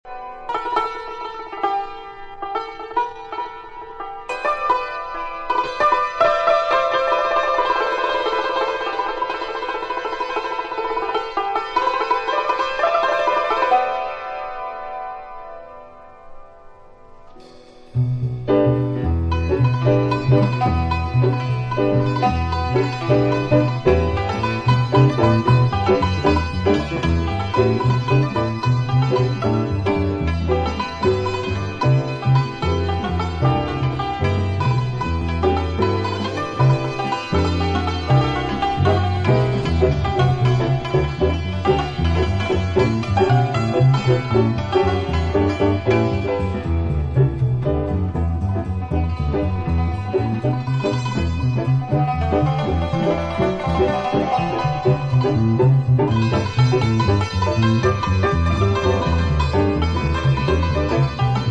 Spiritual jazz, Eastern jazz - direct from the source!